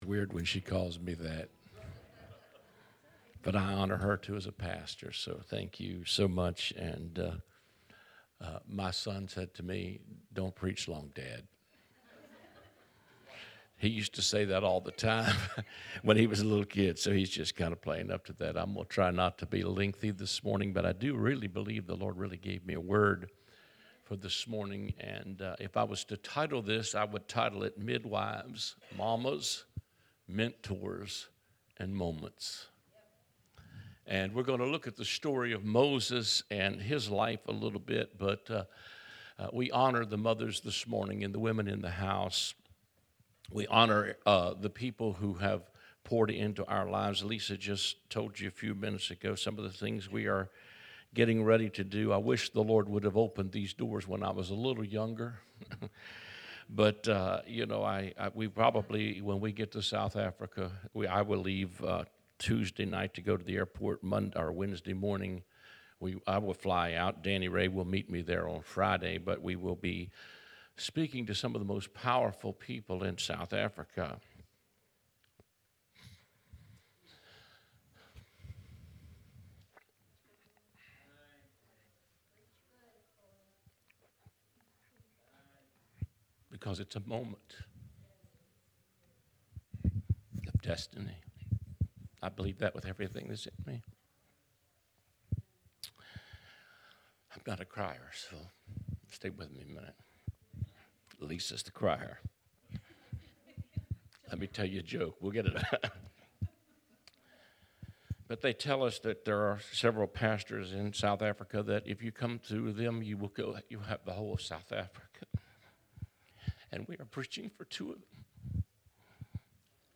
Word of Deliverance Church in Berkeley Springs WV.
Guest Speaker